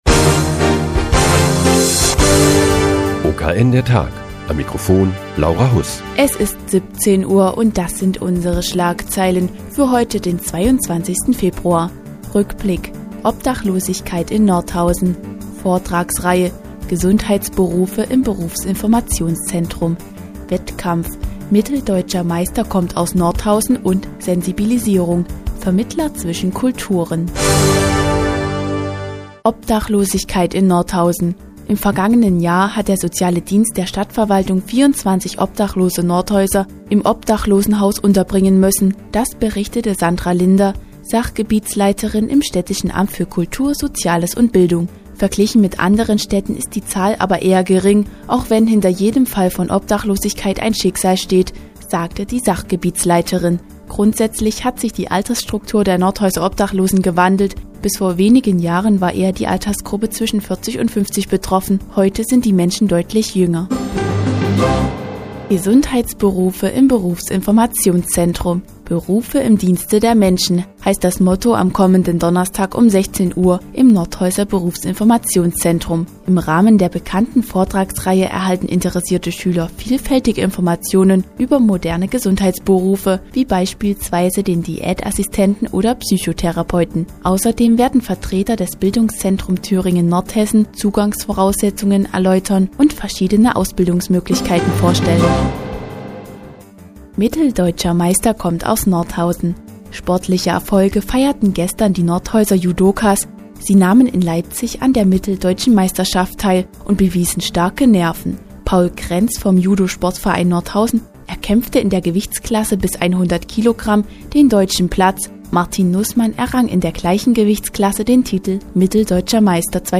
Die tägliche Nachrichtensendung des OKN ist nun auch in der nnz zu hören. Heute geht es um Obdachlosigkeit in Nordhausen und die sportlichen Erfolge der Nordhäuser Judokas.